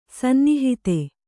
♪ sannihite